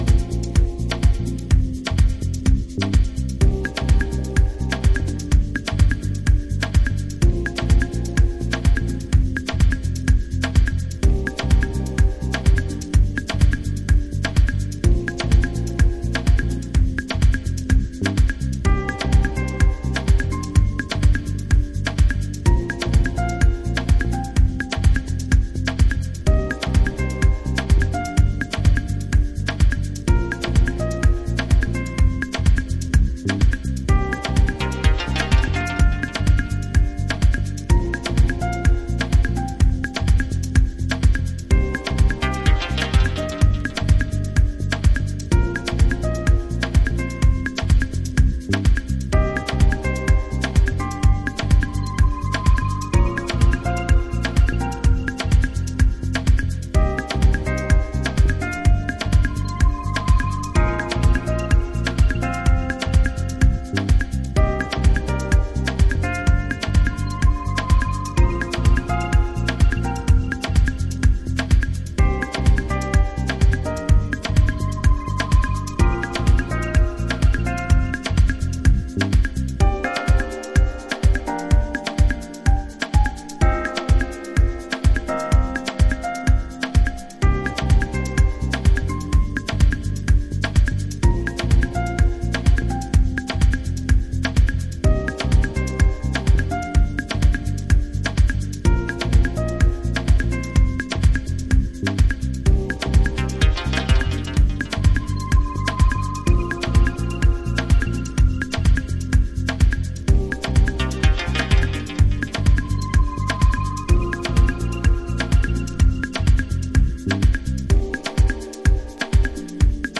深い時間に沁み込みそうなディープハウス。